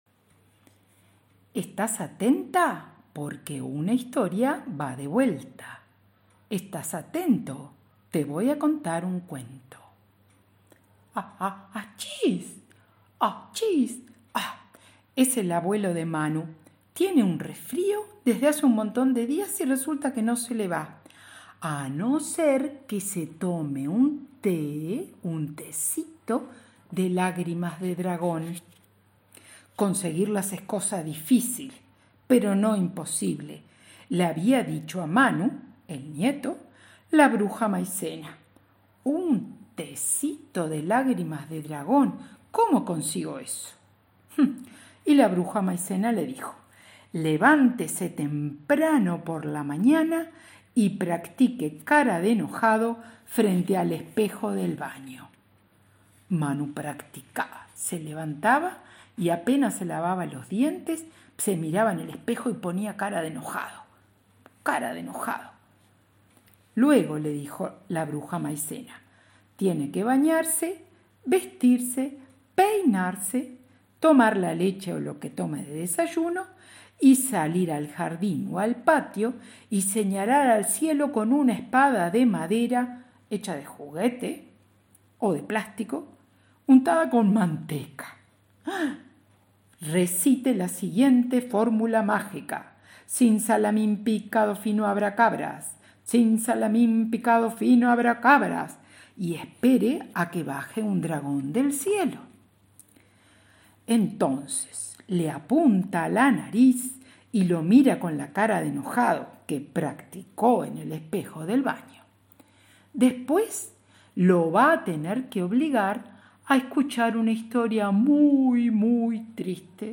Cuento Tecito lágrimas de dragón
tecito_lagrimas_de_dragon._cuento.mp3